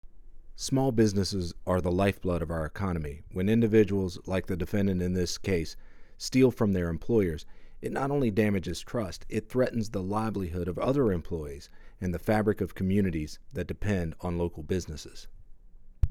Click here to listen to an audio clip from U.S. Attorney Booth Goodwin regarding today’s sentencing